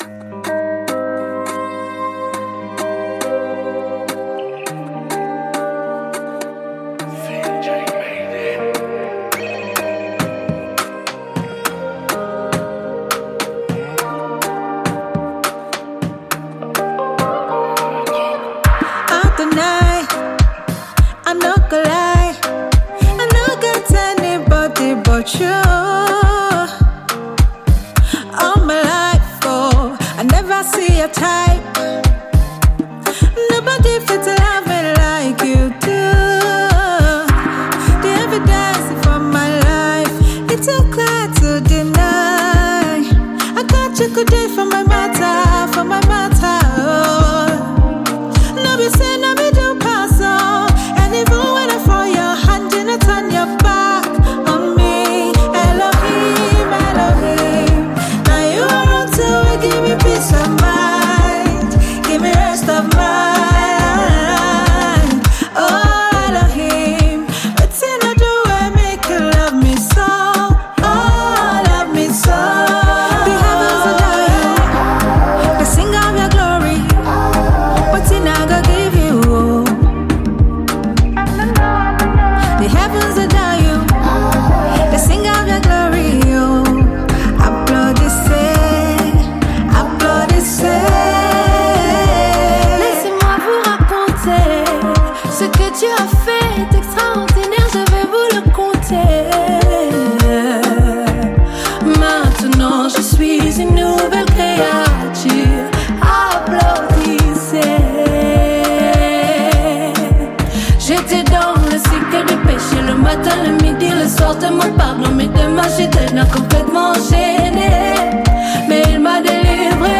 gospel music
gospel singer
There are nine soul-stirring tracks on this new CD.